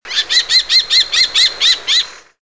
Faucon crécerelle
Falco  tinnunculus
crecerelle.mp3